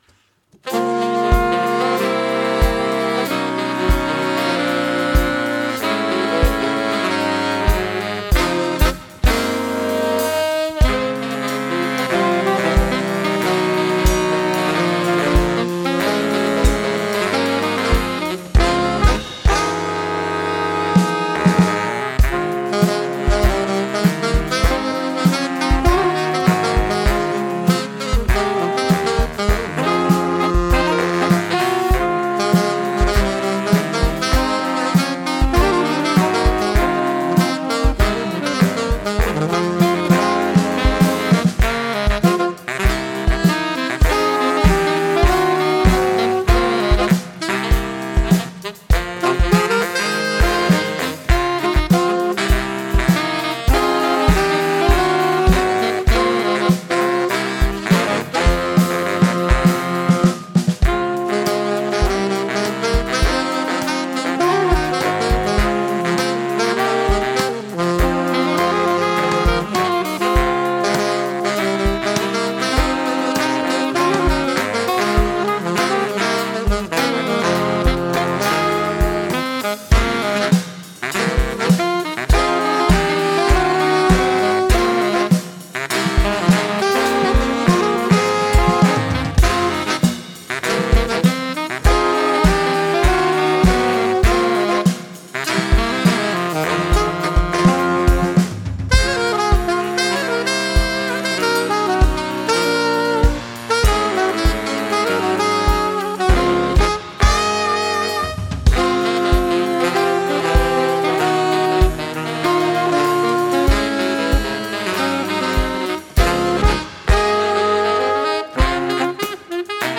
Gattung: für Saxophonquartett oder -quintett
Besetzung: Instrumentalnoten für Saxophon